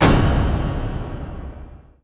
loginok.wav